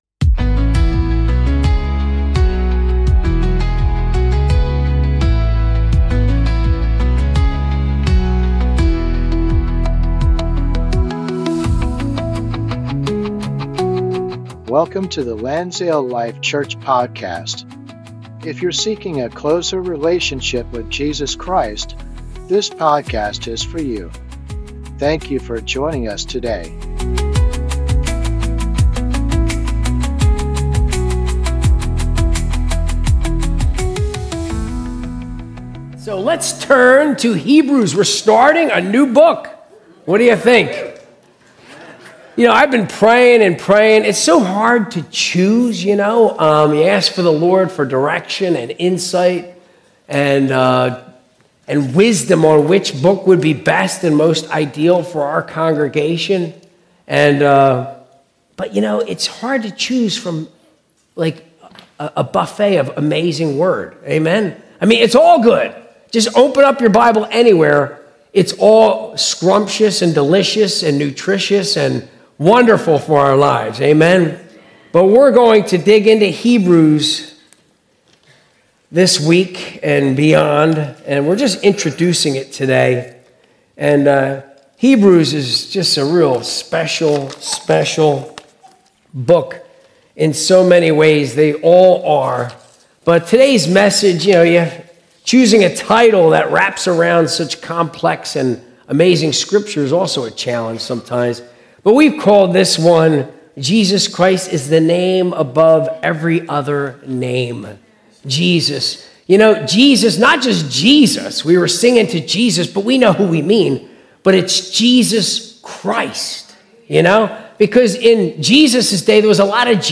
Sunday Service - 2025-06-01